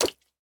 Minecraft Version Minecraft Version 25w18a Latest Release | Latest Snapshot 25w18a / assets / minecraft / sounds / mob / frog / long_jump1.ogg Compare With Compare With Latest Release | Latest Snapshot
long_jump1.ogg